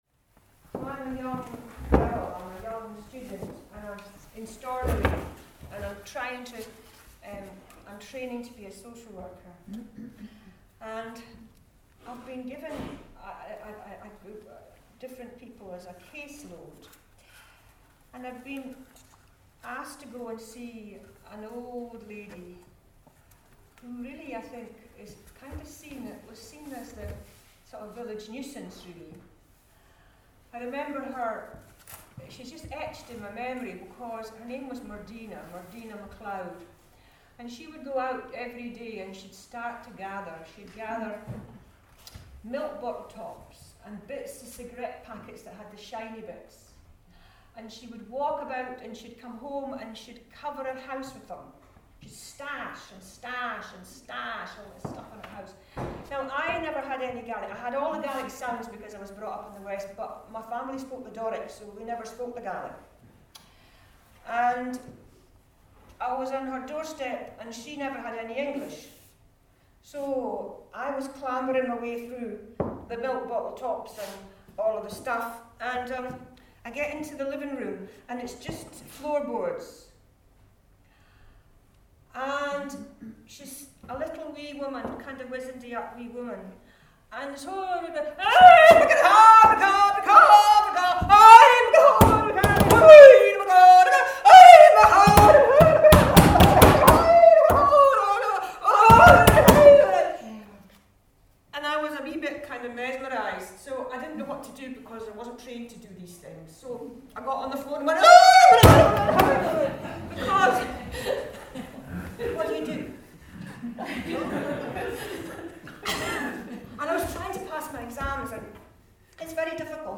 Personal story